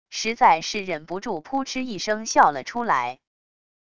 实在是忍不住扑哧一声笑了出来wav音频生成系统WAV Audio Player